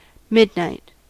Ääntäminen
Ääntäminen US : IPA : [ˈmɪd.naɪt]